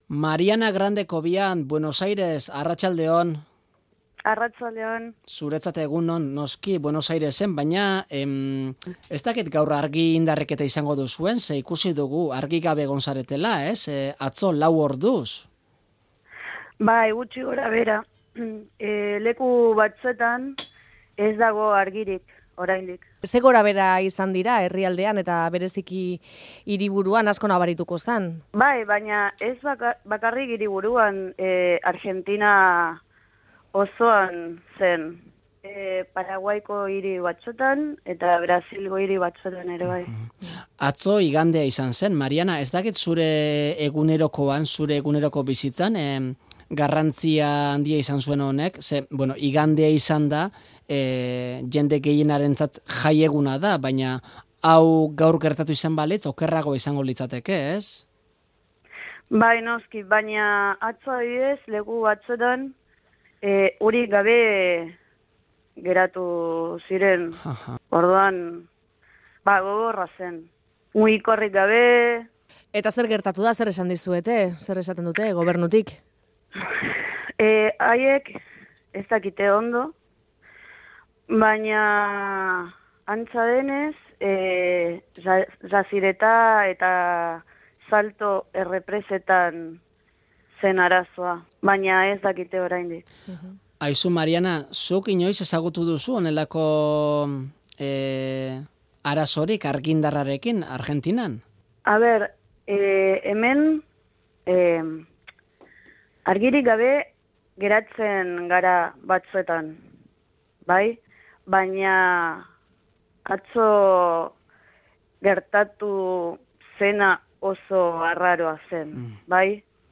Euskara azento porteñoaz: Buenos Airestik inoiz EHra etorri gabe euskaldun.